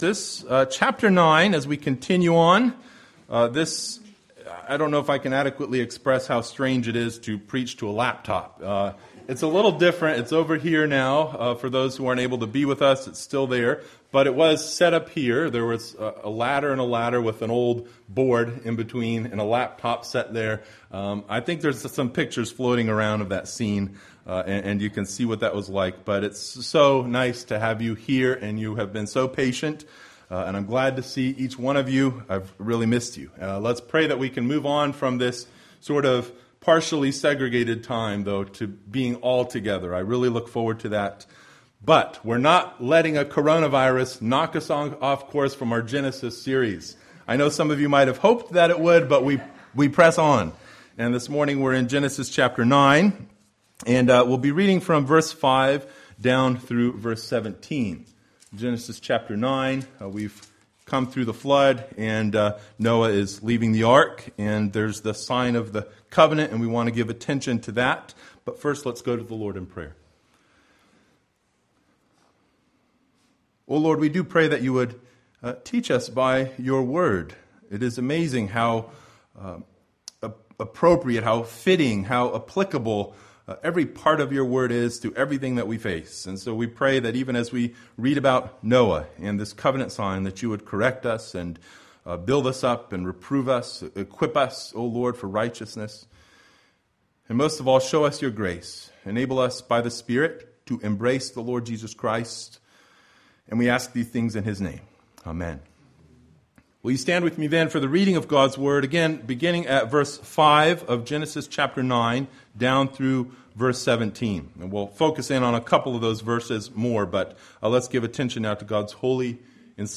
Genesis 9:5-17 Service Type: Sunday Morning Bible Text